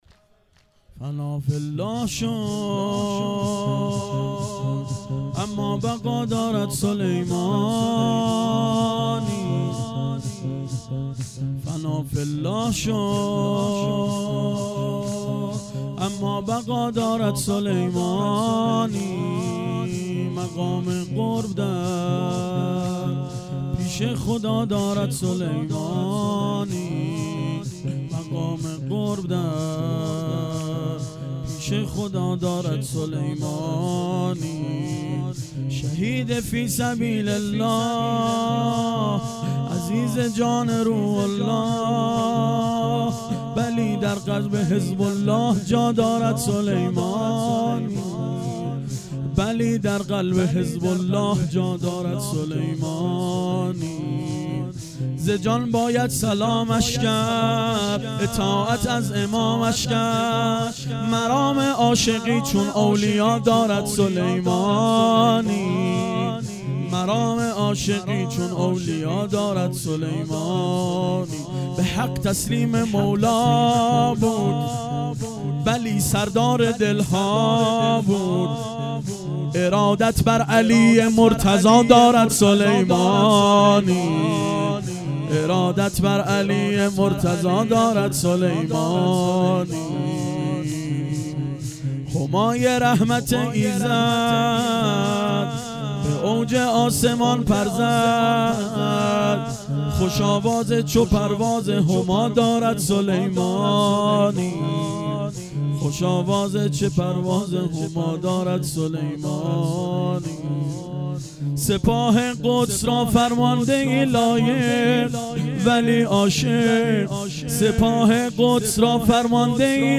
شور شب نهم